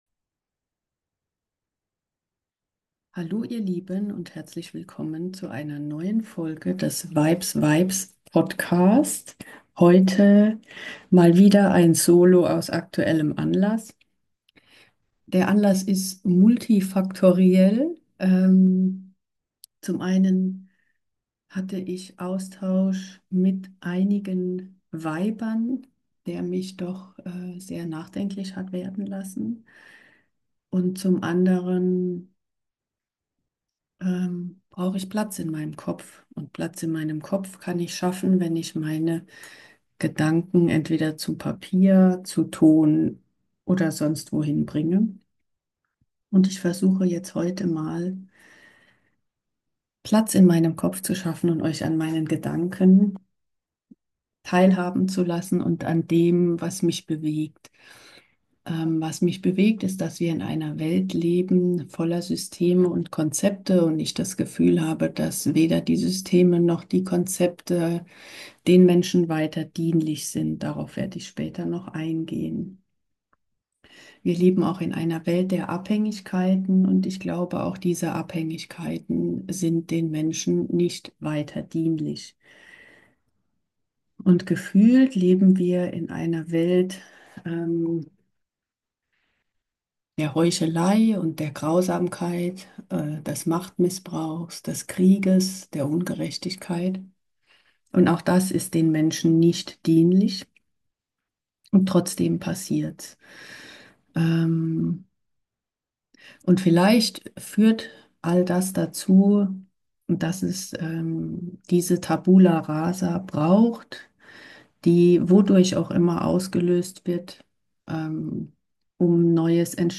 Brauchst du Halt im Außen oder kannst du dich selbst halten? Darüber rede ich heute. Mit mir.